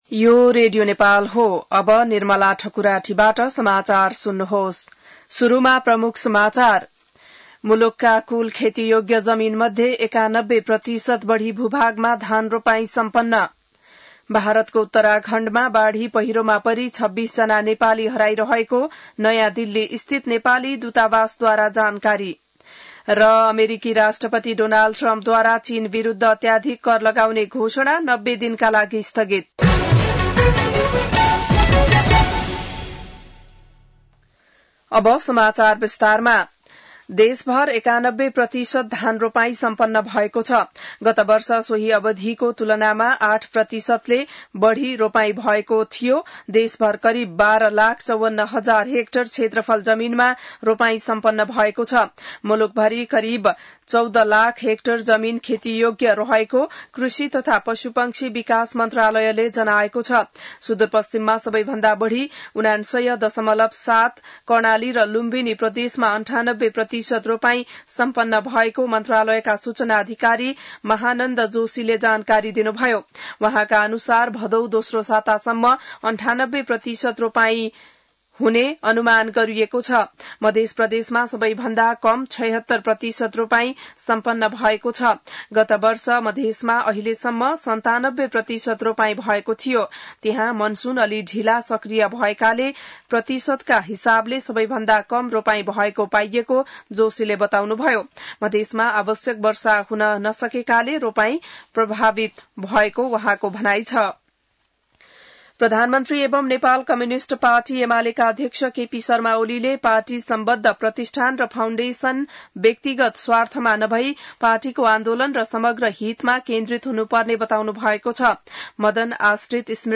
बिहान ९ बजेको नेपाली समाचार : २७ साउन , २०८२